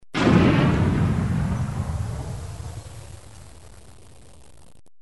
دانلود صدای بمب و موشک 29 از ساعد نیوز با لینک مستقیم و کیفیت بالا
جلوه های صوتی